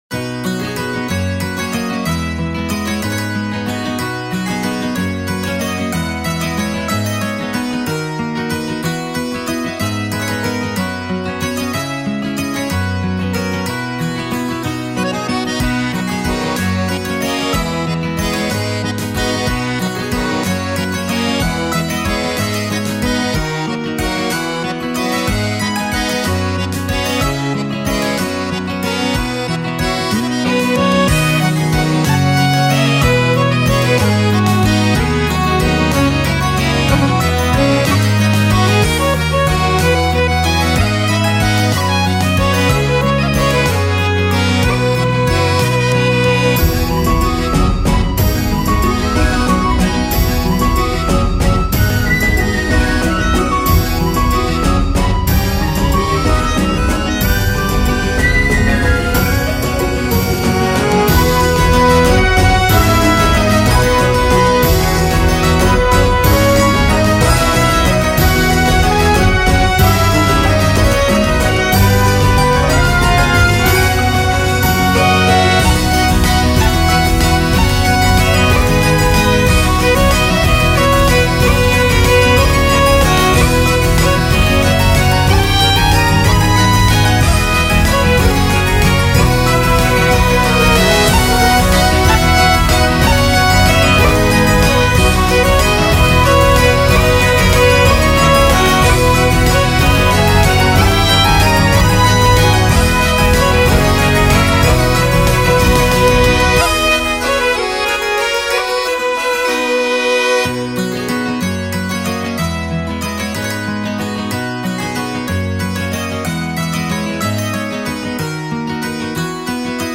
ジャンルケルト風音楽
BPM１８６
使用楽器フィドル、アコーディオン、ティンホイッスル
解説明るく穏やかなケルト風フリーBGMです。